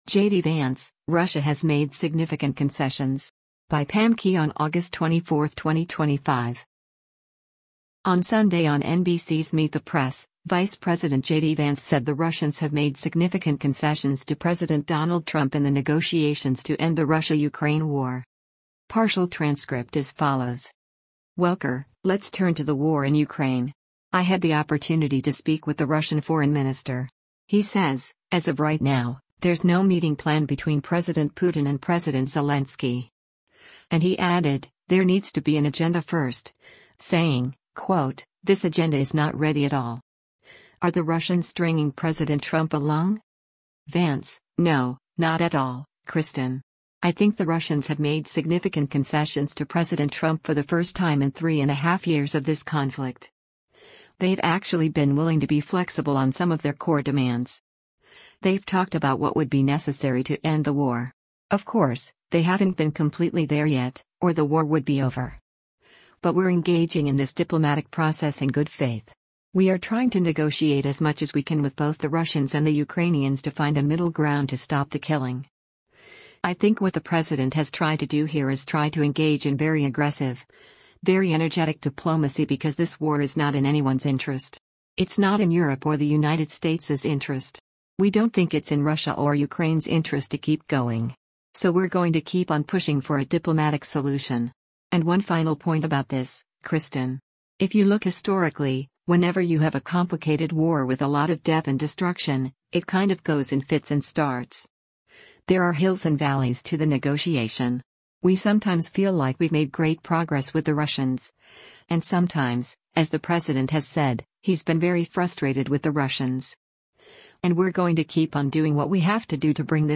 On Sunday on NBC’s “Meet the Press,” Vice President JD Vance said the Russians have made “significant concessions” to President Donald Trump in the negotiations to end the Russia-Ukraine war.